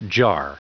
Prononciation du mot jar en anglais (fichier audio)
Prononciation du mot : jar